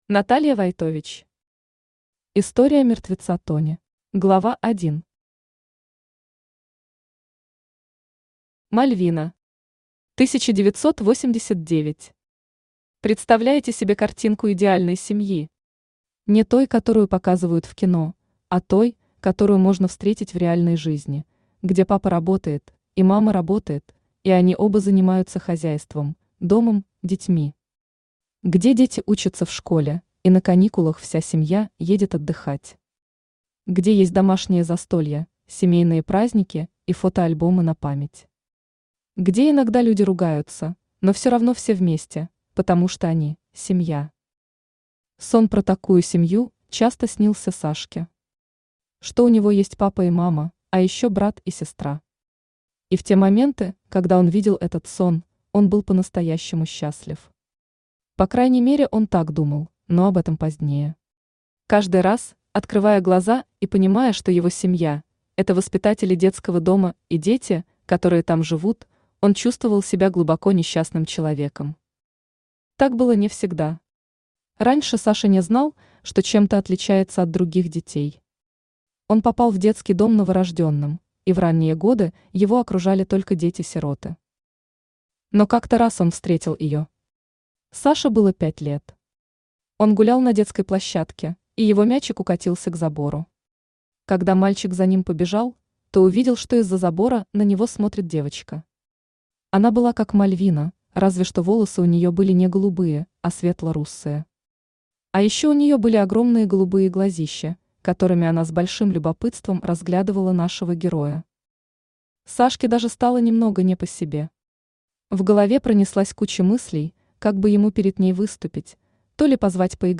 Аудиокнига История Мертвеца Тони | Библиотека аудиокниг
Aудиокнига История Мертвеца Тони Автор Наталья Войтович Читает аудиокнигу Авточтец ЛитРес.